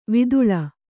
♪ viduḷa